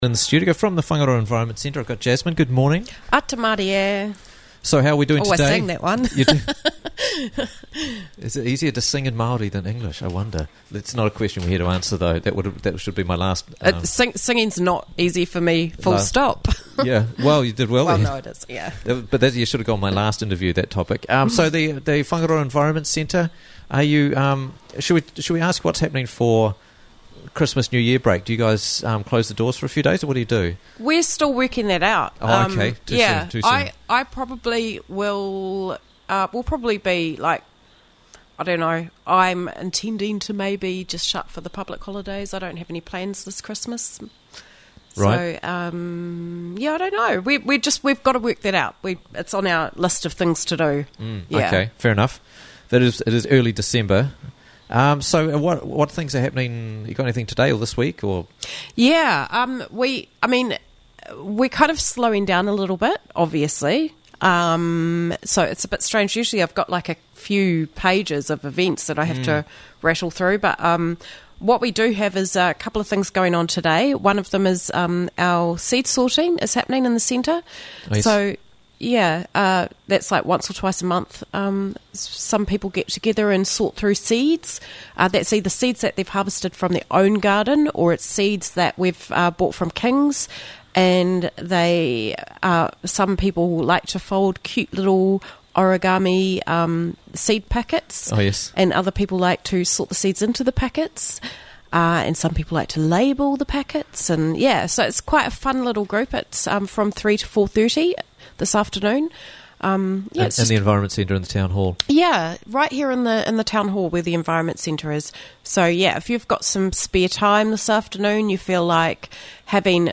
is in studio to talk about this week's events at the centre and in the community